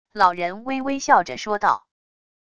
老人微微笑着说道wav音频生成系统WAV Audio Player